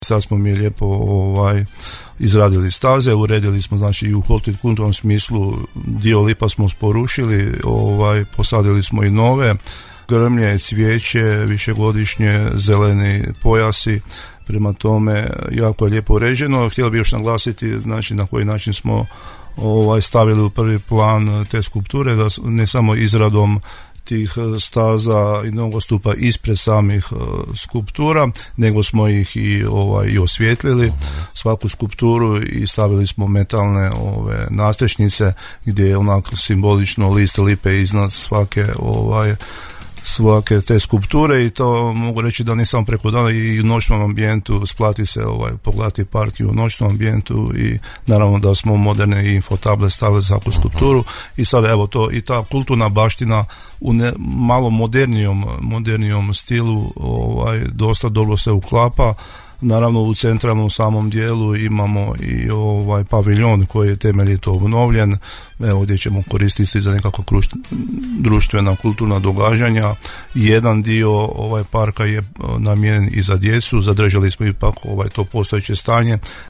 – rekao je načelnik Općine Podravske Sesvete, Mladen Sitek, za Podravski radio.